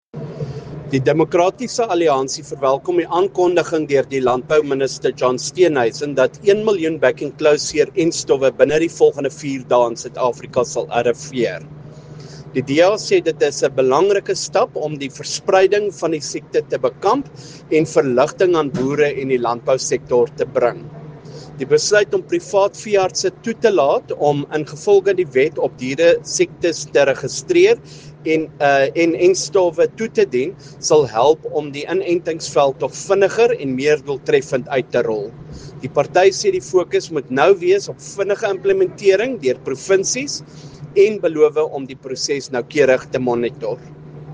Issued by Beyers Smit MP – DA Spokesperson on Agriculture
Afrikaans audio clips by Beyers Smit MP.